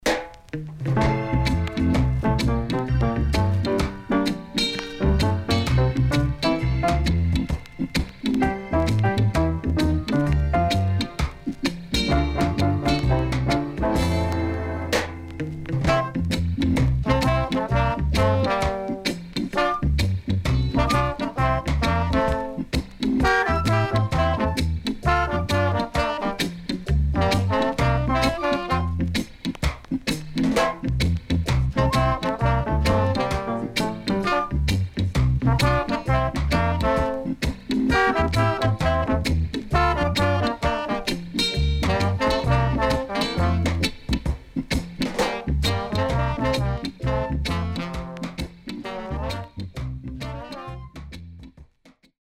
HOME > REGGAE / ROOTS
Nice Mellow
SIDE A:少しチリノイズ、プチノイズ入ります。